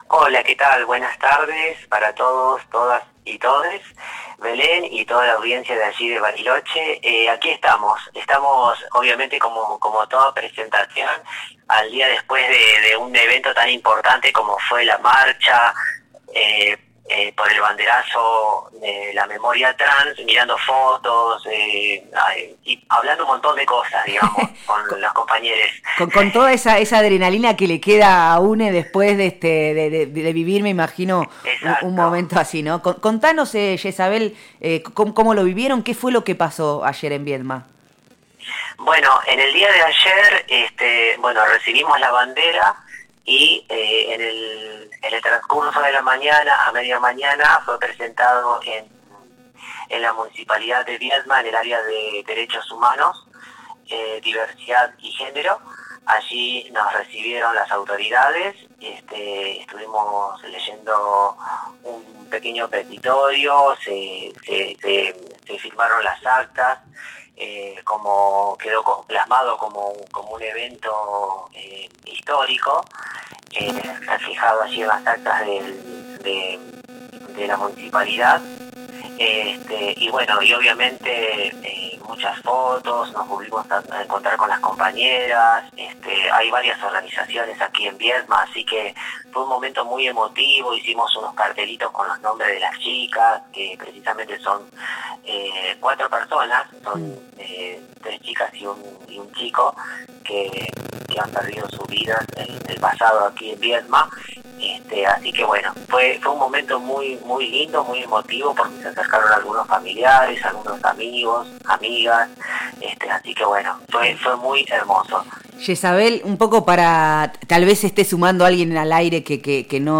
integrante de Mujeres Trans de Viedma en diálogo con Nosotres les Otres habló sobre el Banderazo por la memoria trans que llegó a la capital rionegrina.